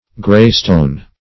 Search Result for " graystone" : The Collaborative International Dictionary of English v.0.48: Graystone \Gray"stone`\, n. (Geol.) A grayish or greenish compact rock, composed of feldspar and augite, and allied to basalt.